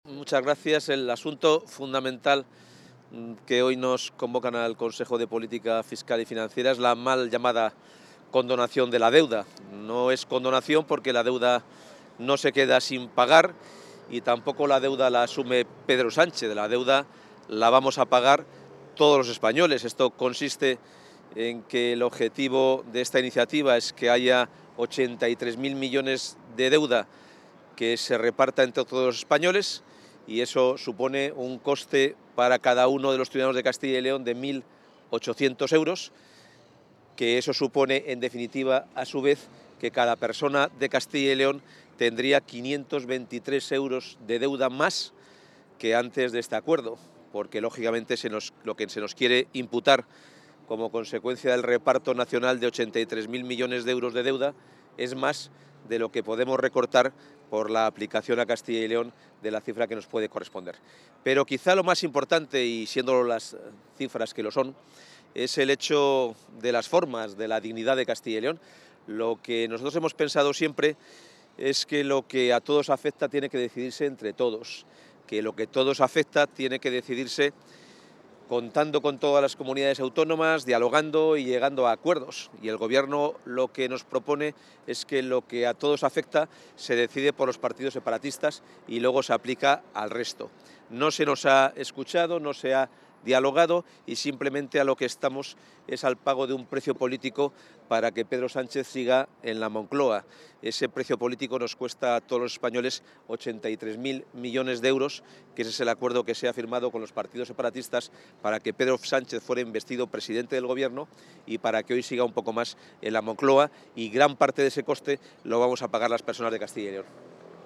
Declaraciones previas al Consejo de Política Fiscal y Financiera
Declaraciones previas del consejero.